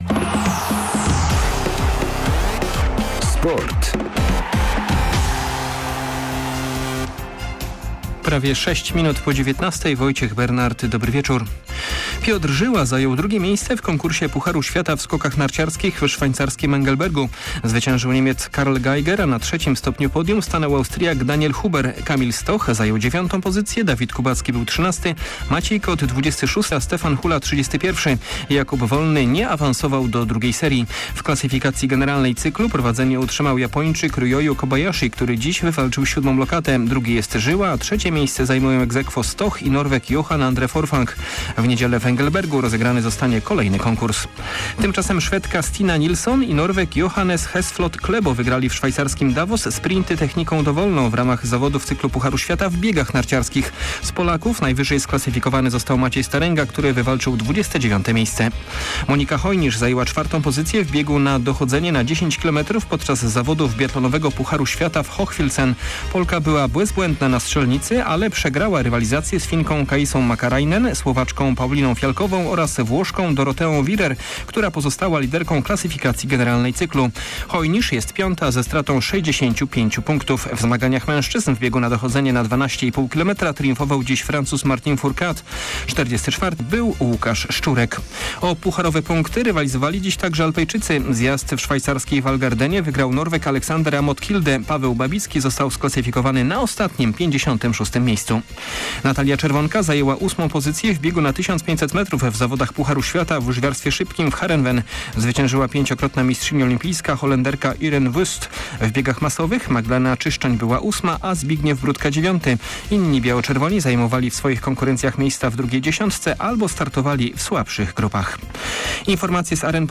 15.12. SERWIS SPORTOWY GODZ. 19:05